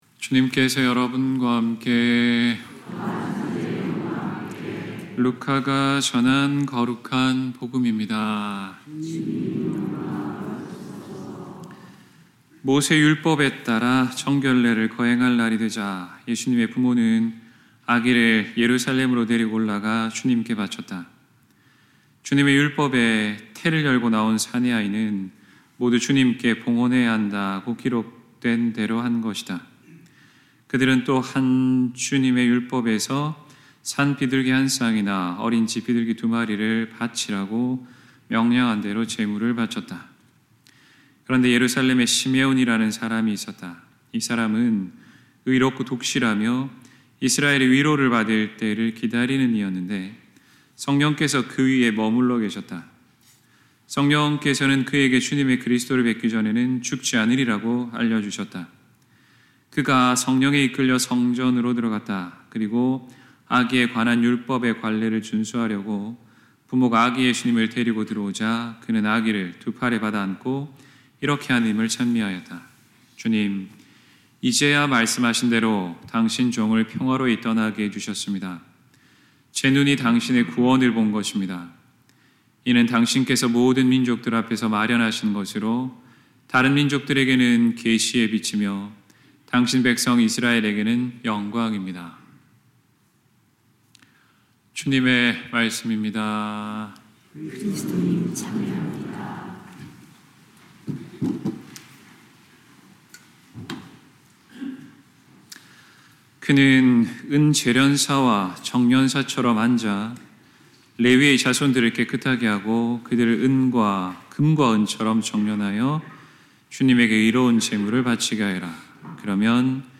2025년 2월 2일 주님 봉헌 축일 (축성 생활의 날 ) 신부님 강론